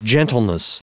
Prononciation du mot gentleness en anglais (fichier audio)
Prononciation du mot : gentleness